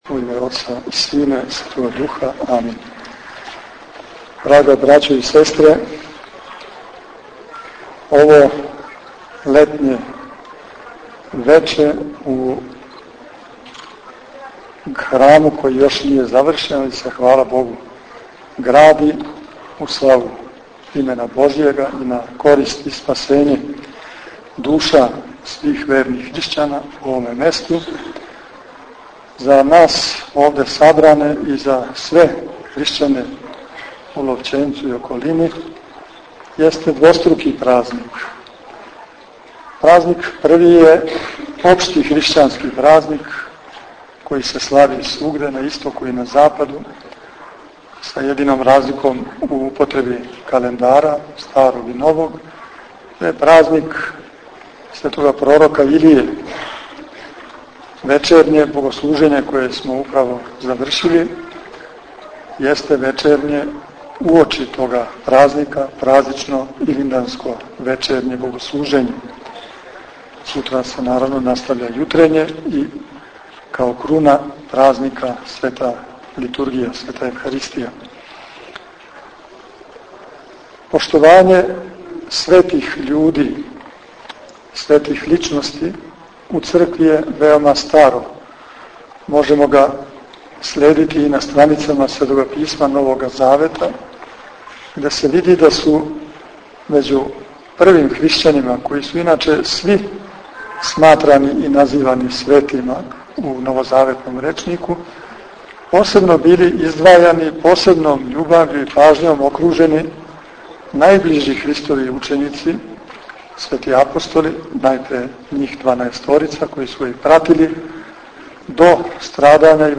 У навечерје празника Светог Пророка Илије, у недељу, 19. јула/1. августа текуће године, Његово Преосвештенство Господин др Иринеј, православни Епископ бачки, освештао је крст за храм Светог Петра Цетињског Чудотворца у Ловћенцу.
Епископ је беседио сабранима о личности и врлинама светог пророка Илије.